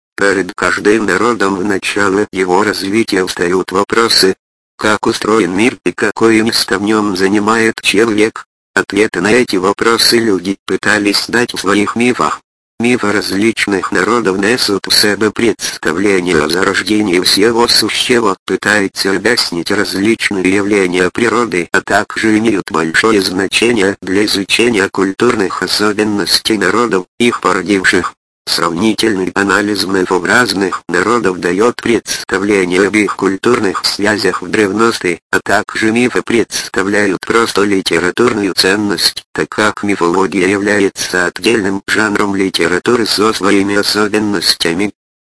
Ukrvox Igor - это синтезатор украинской речи стандарта SAPI5. Построен на базе мужского голоса "Igor".
Однако русскоязычный текст читается с заметным украинским акцентом. Имеется возможность настройки скорости речи, однако на максимальном значении речь остаётся довольно неторопливой, хотя для людей непривычных к синтезированной речи это не будет доставлять большого неудобство.